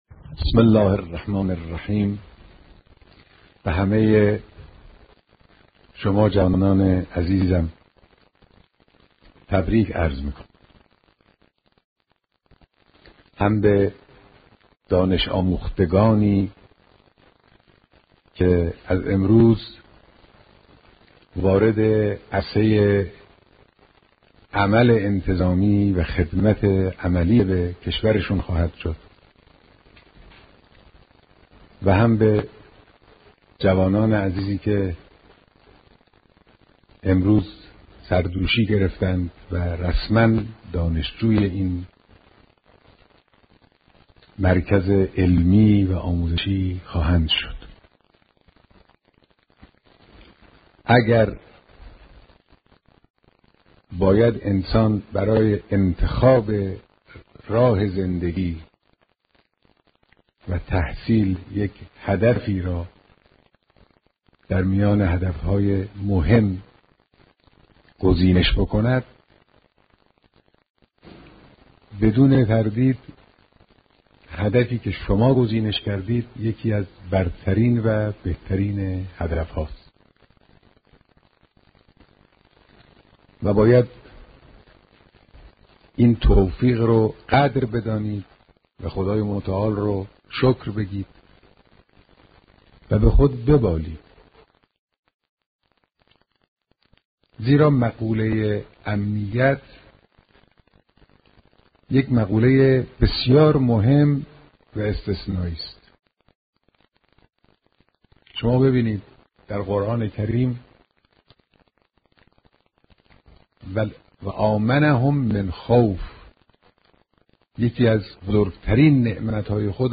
صوت کامل بیانات
مراسم دانش‌آموختگی دانشجویان دانشگاه علوم انتظامی